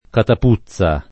catapuzia
vai all'elenco alfabetico delle voci ingrandisci il carattere 100% rimpicciolisci il carattere stampa invia tramite posta elettronica codividi su Facebook catapuzia [ katap 2ZZL a ] o catapuzza [ katap 2ZZ a ] s. f. (bot.)